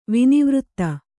♪ vinivřtta